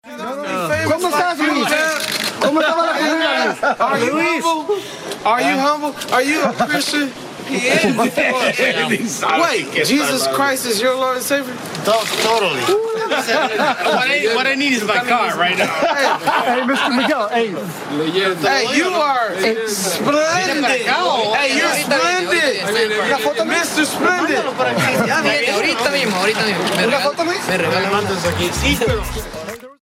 AUDIO Luis Miguel con sus fans a la salida de hotel con una chica!
El Sol de México habla en inglés y acepta los saludos y los pedidos de fotos con sus fans.